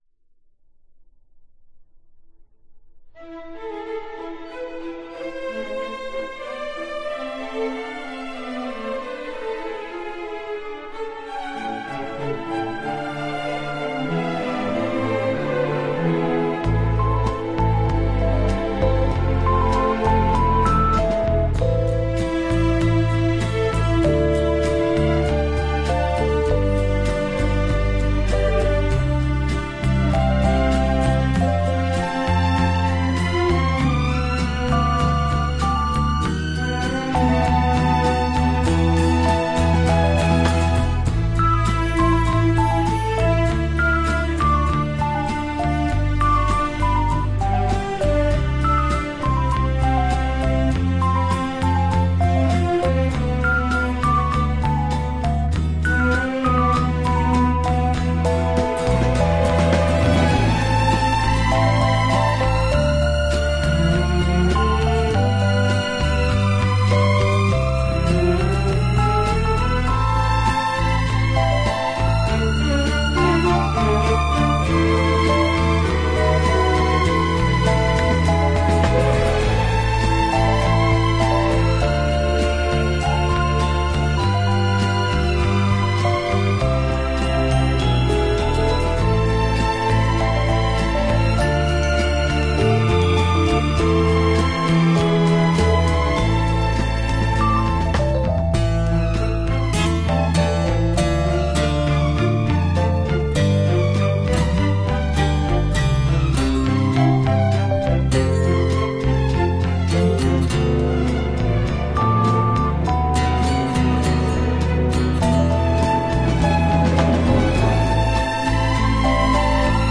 Жанр: Pop,Easy Listening